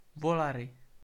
Volary (Czech pronunciation: [ˈvolarɪ]